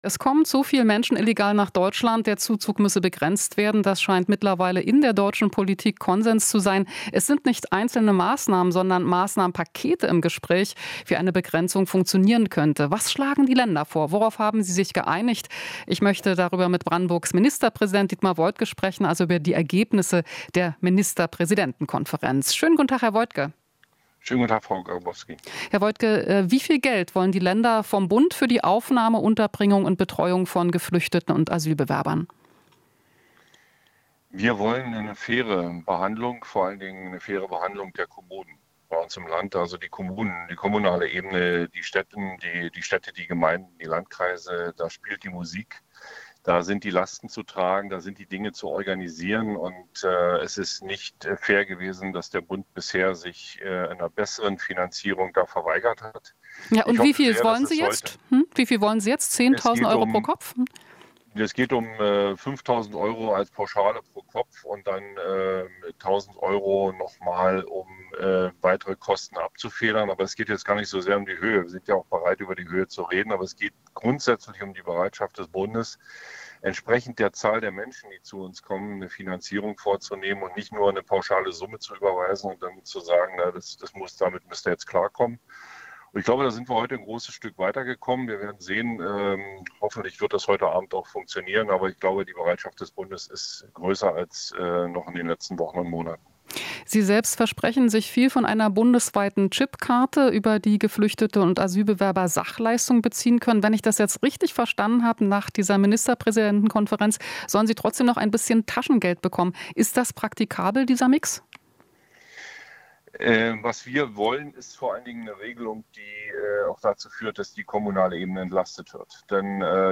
Interview - Woidke: Chipkarten für Geflüchtete können Kommunen entlasten